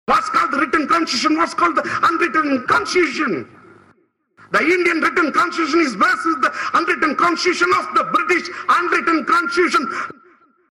What we needed now is something within these speeches that would serve as a chorus and we picked this
trvoice.mp3